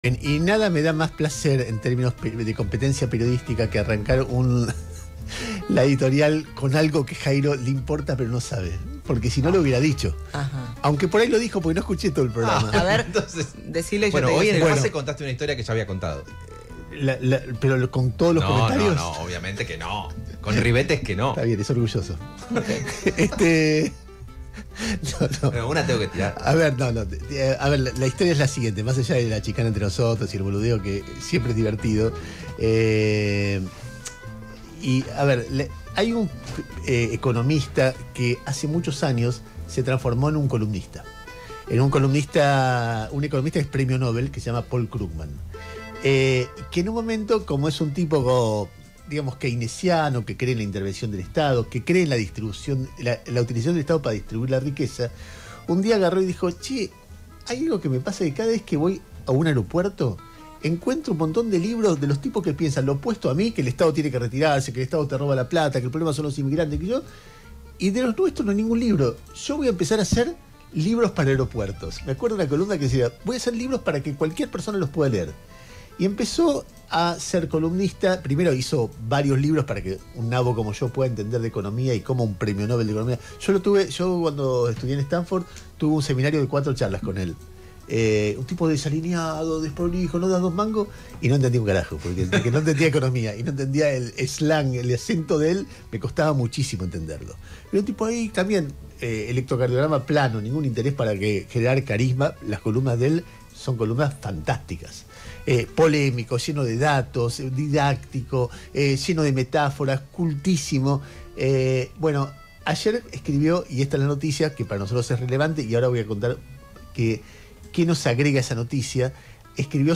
El-resentimiento-de-los-poderosos-editorial-de-Ernesto-Tenembaum-en-YAQPA.ogg